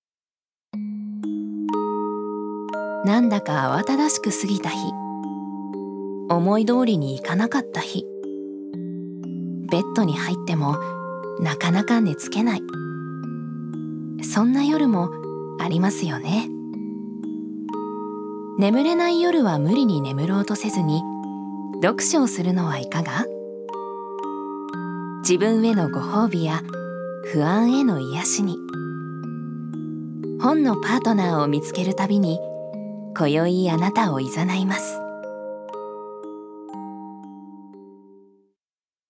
女性タレント
ナレーション３